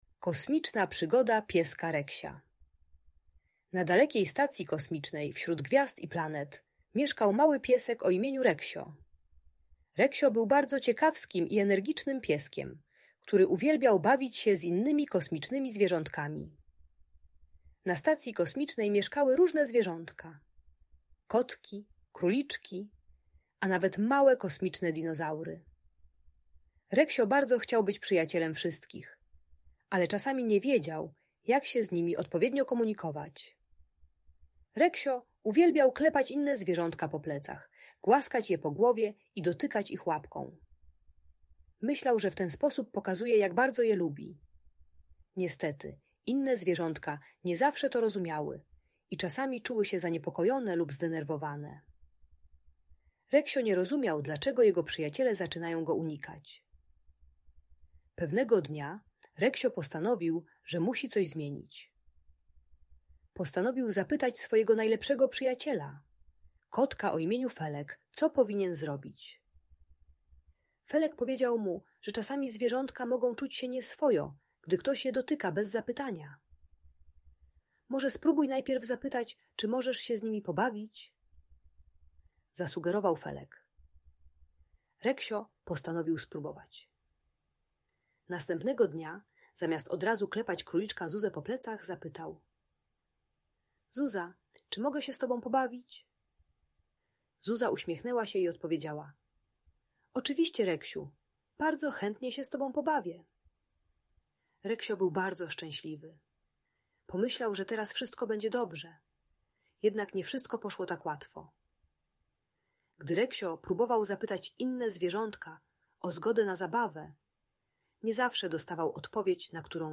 Kosmiczna Przygoda Pieska Reksia - historia o przyjaźni - Audiobajka